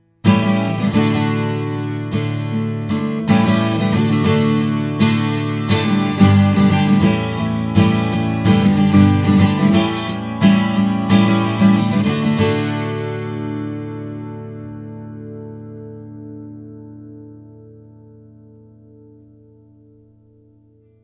12 string Spruce/Aged Eucalyptus, now with sound
A 12 string M-sized Jumbo, with German bear claw spruce and Spanish "aged" Eucalyptus.
Picking